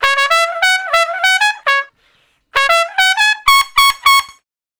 087 Trump Straight (Db) 03.wav